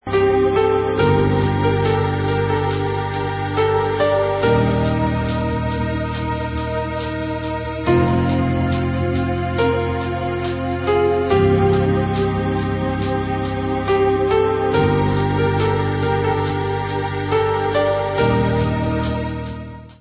Synthie-pop